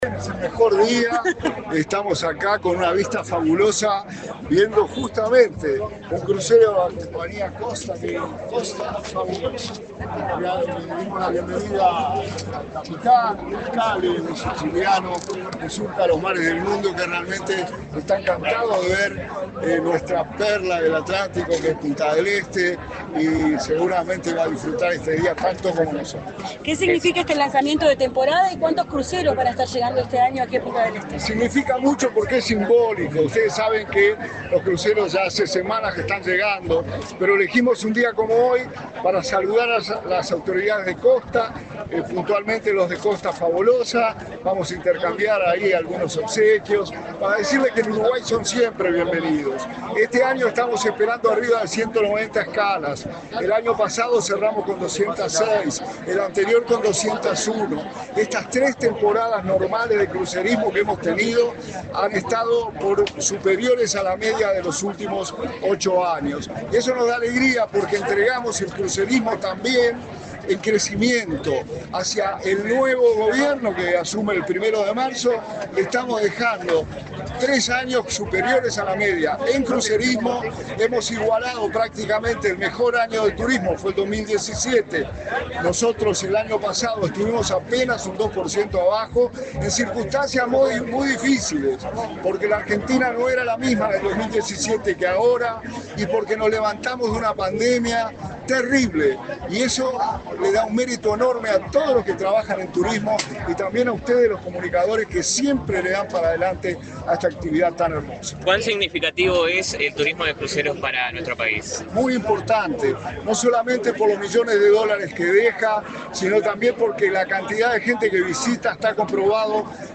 Declaraciones del subsecretario de Turismo, Remo Monzeglio
Este jueves 12 en Punta del Este, Maldonado, el subsecretario de Turismo, Remo Monzeglio, dialogó con la prensa, luego de participar del lanzamiento